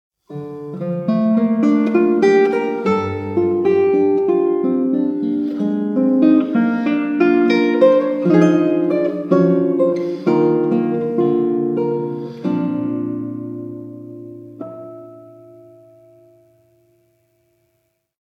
The Hunting of the Snark (EN) audiokniha
Ukázka z knihy